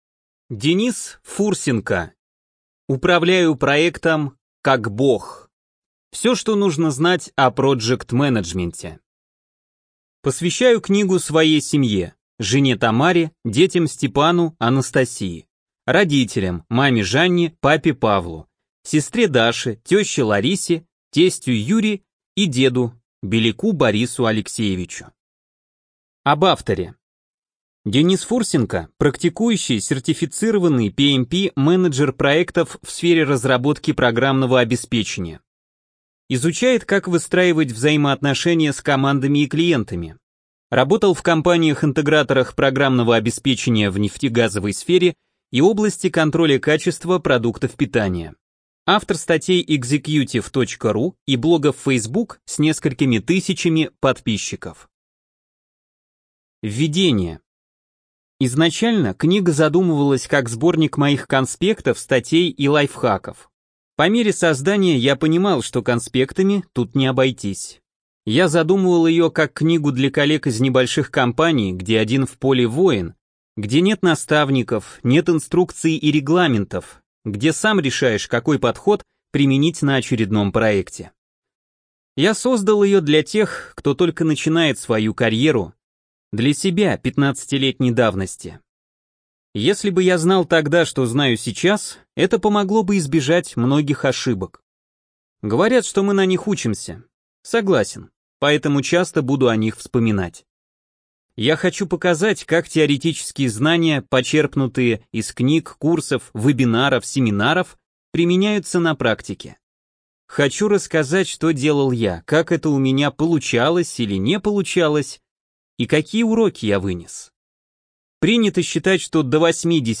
ЖанрДеловая литература